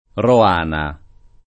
Roana [ ro # na ]